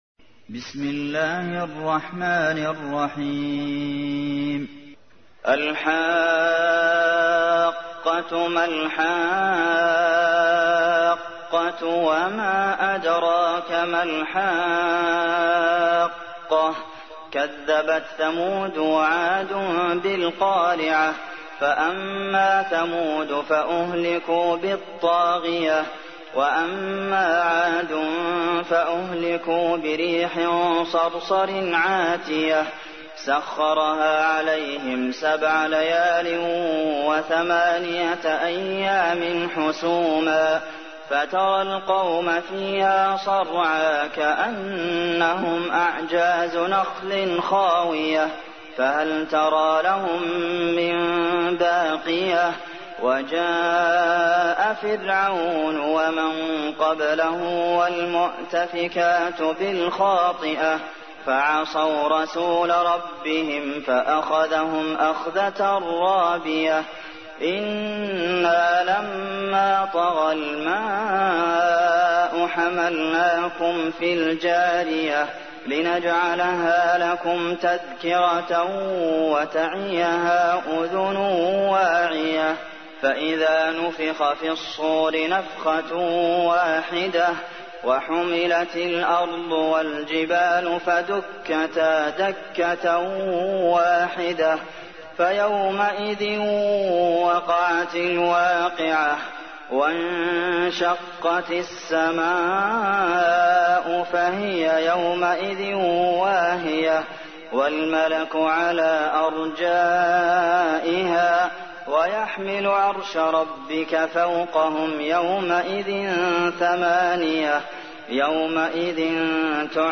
تحميل : 69. سورة الحاقة / القارئ عبد المحسن قاسم / القرآن الكريم / موقع يا حسين